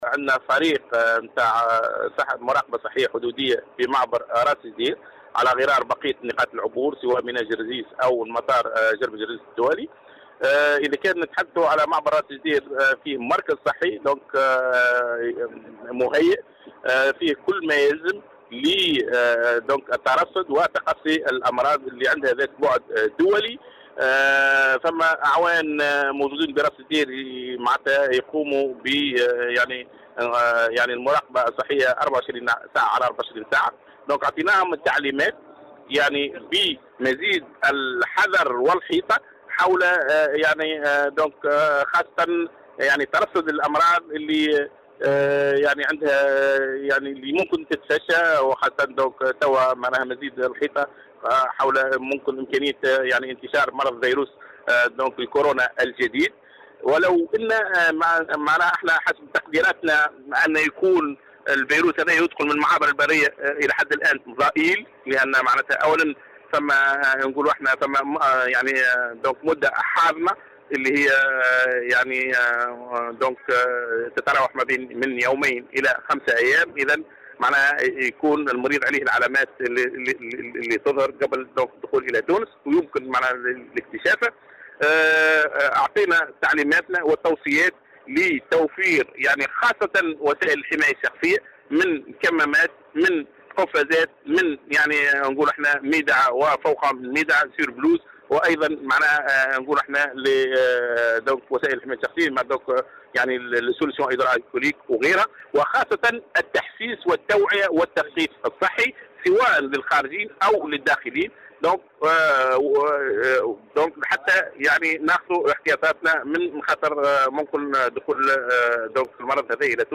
وأضاف في تصريح اليوم لمراسل "الجوهرة أف أم" أنه تم أخذ الاحتياطات اللازمة و رفع درجة اليقظة ودعم المركز الصحي بالموارد البشرية وتوفير وسائل الحماية من كمّامات طبية وقفازات وغيرها..